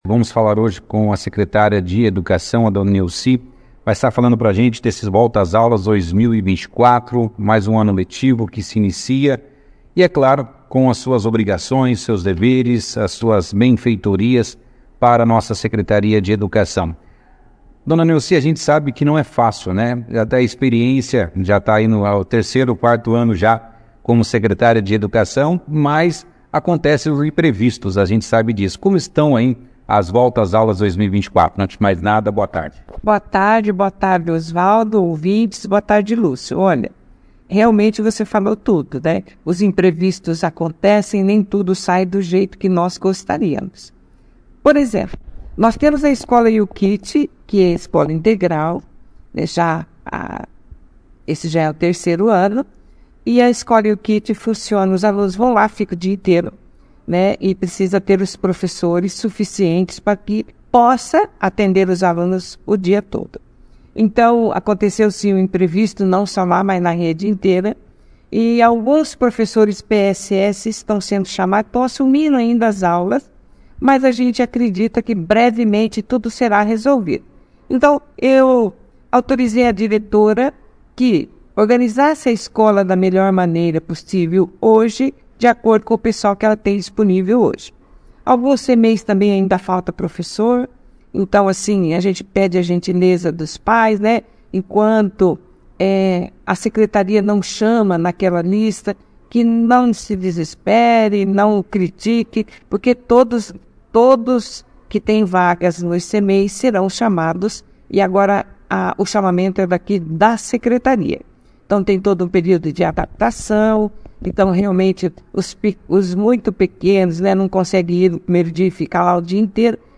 A Professora Nelci Maria Martins de Queiroz, Secretária de Educação e Cultura de Bandeirantes, participou da 2ª edição do Jornal Operação Cidade nesta segunda-feira, 05 de fevereiro. Durante a entrevista, ela detalhou os imprevistos enfrentados neste primeiro dia do novo ano letivo nas escolas municipais e CEMEIS, enfatizando a necessidade de ajustes devido à escassez de professores para as aulas integrais.